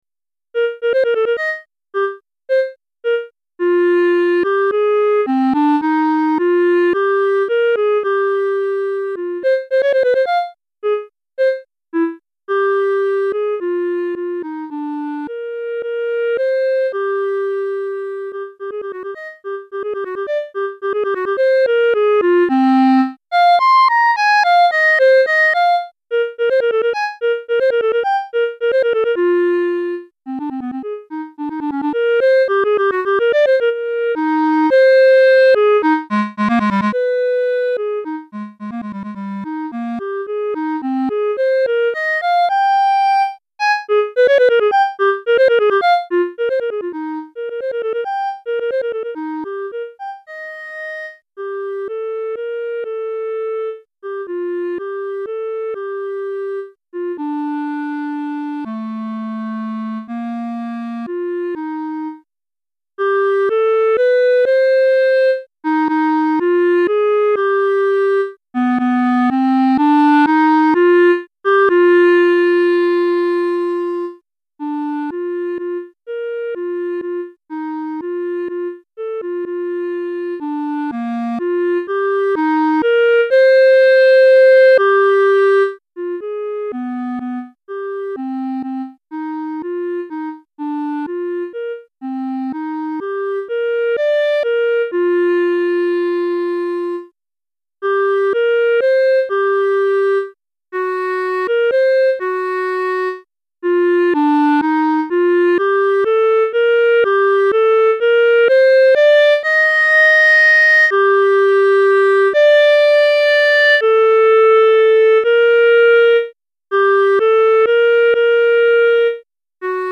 Pour Clarinette solo DEGRE CYCLE 2